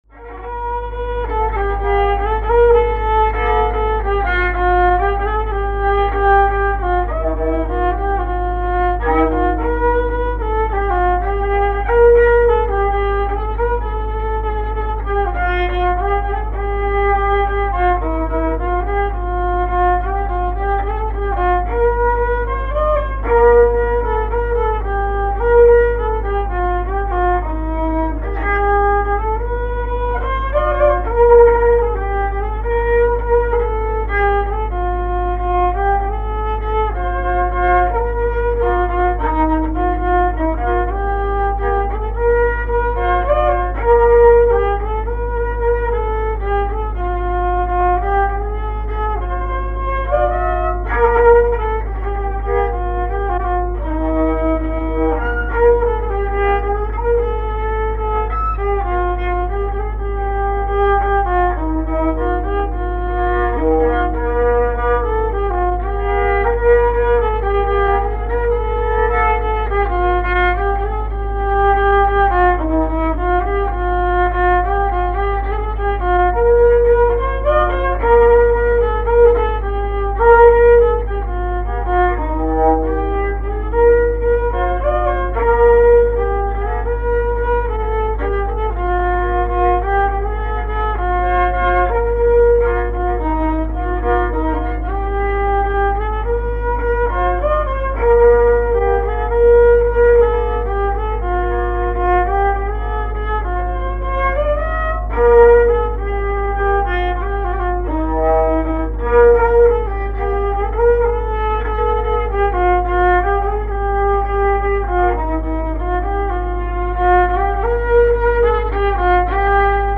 Fonction d'après l'analyste danse : valse ;
Répertoire de musique traditionnelle
Pièce musicale inédite